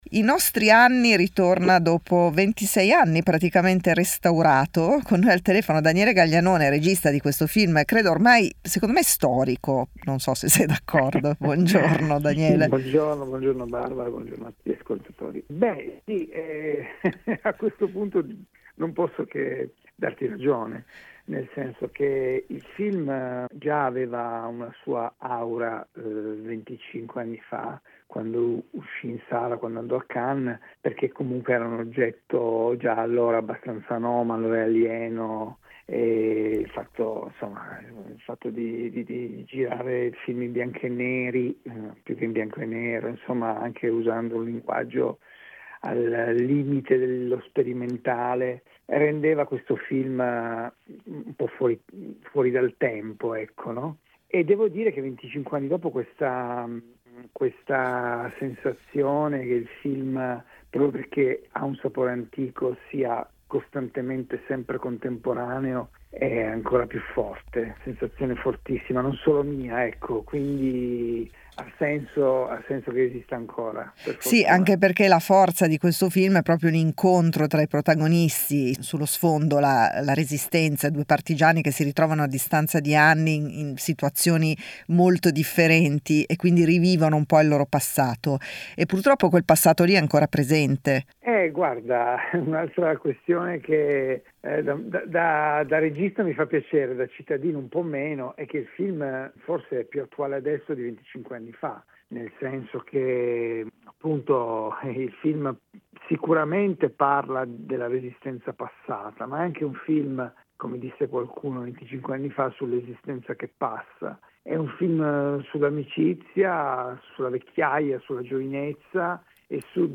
Nell'intervista Daniele Gaglianone ricorda il contesto in cui è nato il film con uno sguardo sul contesto in cui è stato fatto il restauro.